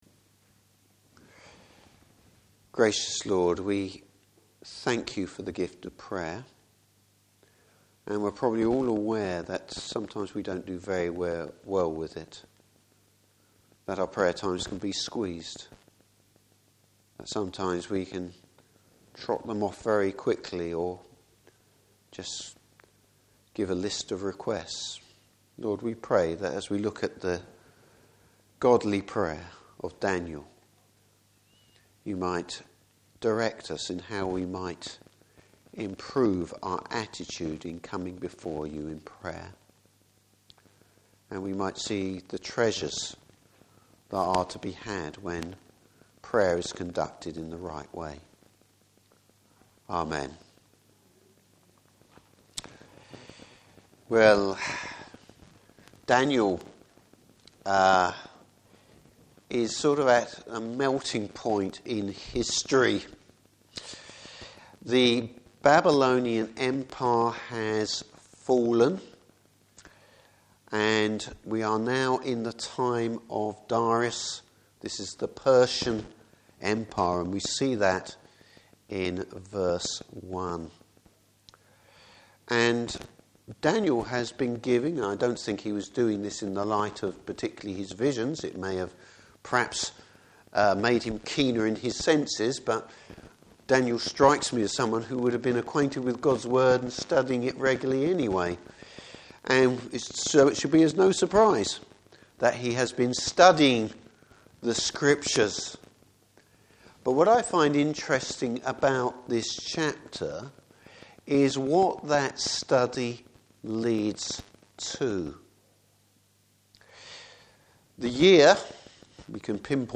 Service Type: Evening Service What can we learn from Daniel’s prayer.